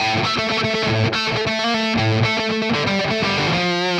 AM_RawkGuitar_120-A.wav